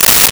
Tear Paper 01
Tear Paper 01.wav